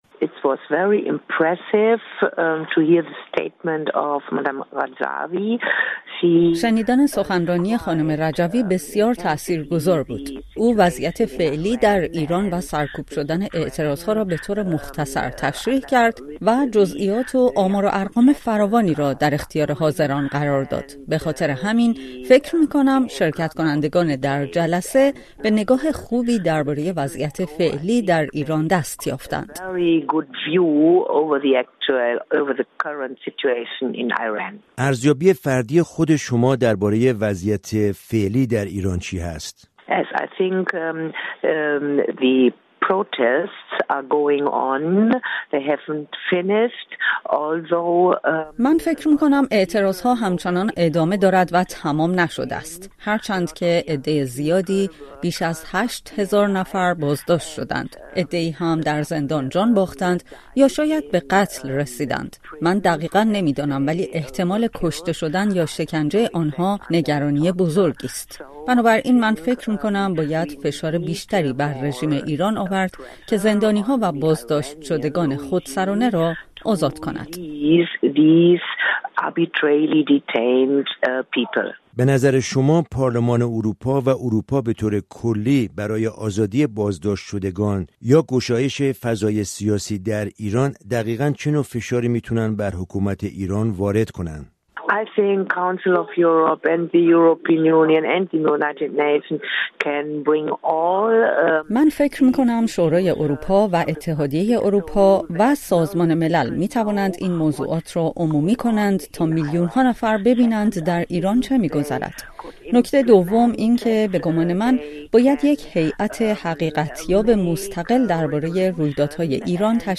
مریم رجوی، از رهبران ارشد سازمان مجاهدین خلق، چهارشنبه در اجلاسی در پارلمان اروپا سخنرانی کرد و درباره اعتراض‌های اخیر در ایران به پرسش‌های نمایندگان پاسخ گفت. رادیو فردا با سابین اشنارنبرگر ، وزیر پیشین دادگستری آلمان،که در این نشست حضور داشته،گفتگو کرده است.